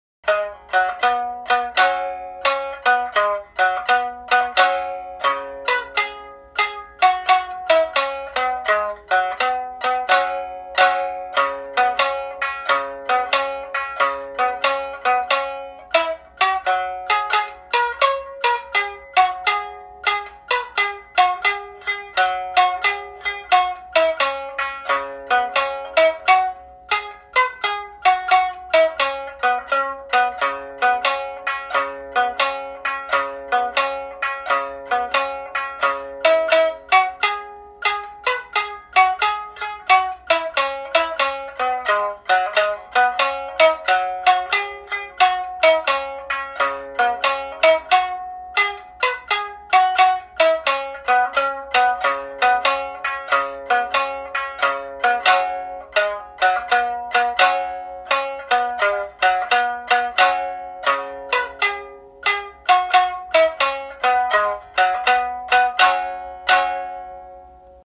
На этой странице собраны звуки сямисэна — уникального трёхструнного инструмента, популярного в японской музыке.
Звучание струн сямисэна